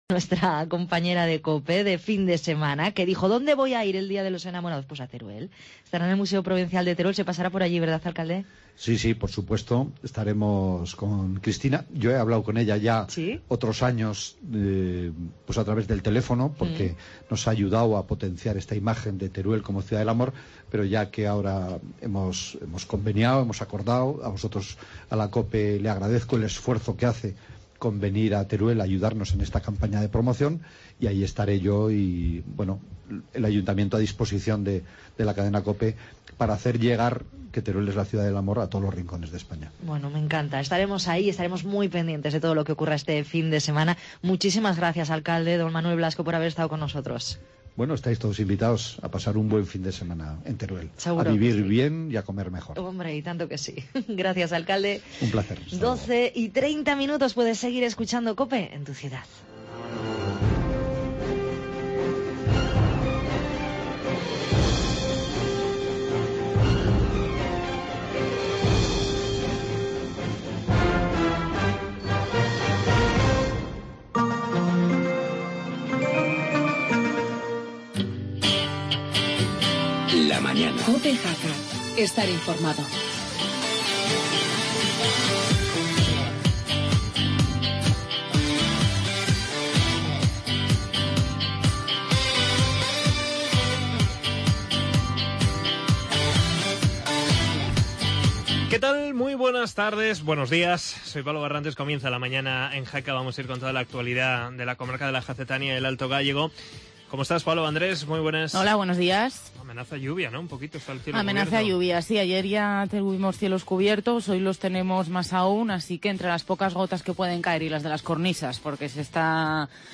entrevista al director de consumo sobre la protección de la tv a los menores